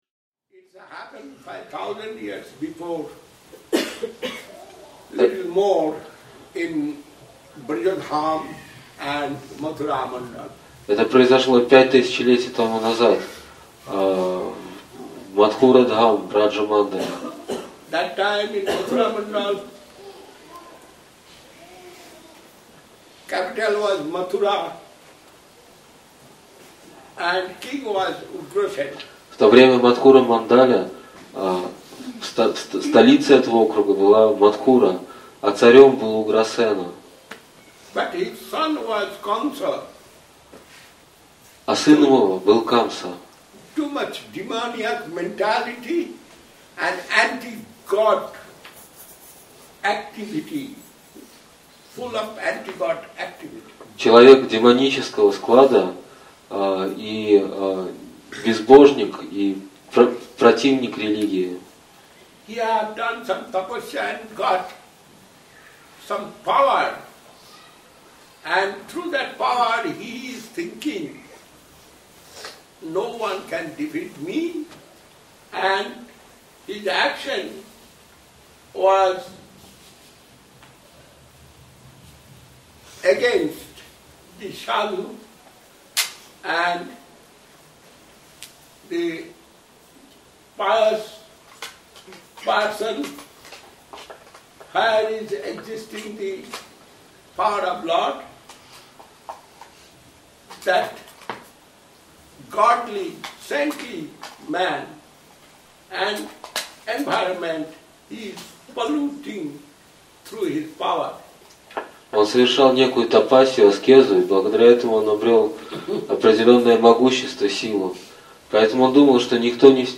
Место: Культурный центр «Шри Чайтанья Сарасвати» Москва